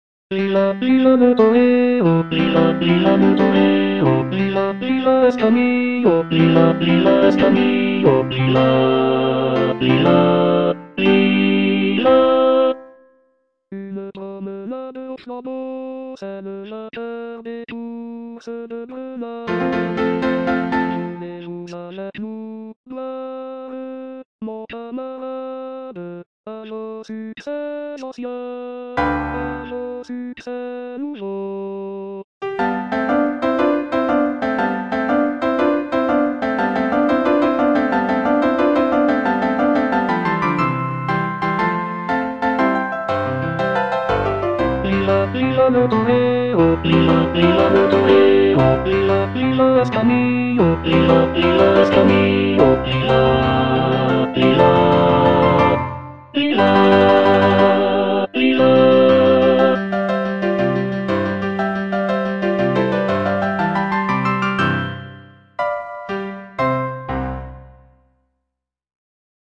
(bass II) (Emphasised voice and other voices) Ads stop